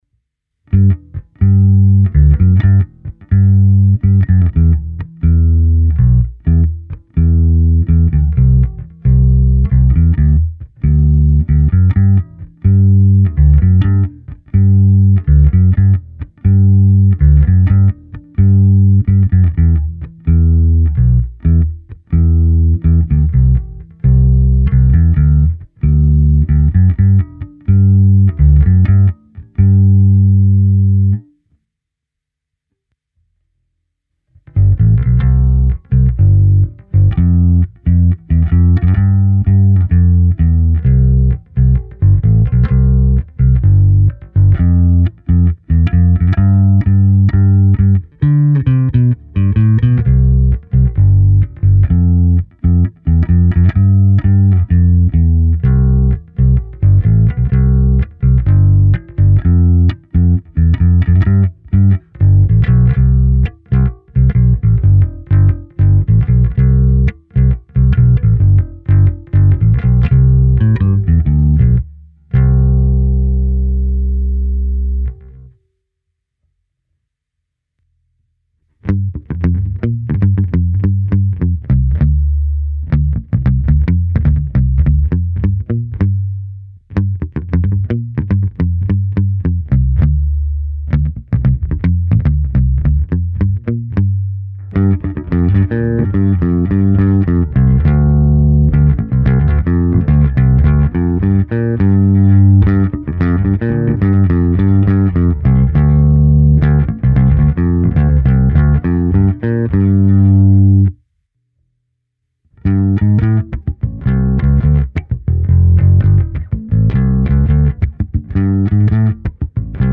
U každých typů strun jsem zvolil jiné ukázky, do části s hlazenkami jsem zakomponoval i mou neumělou hru trsátkem s tlumením a bez.
Simluace aparátu – flatwound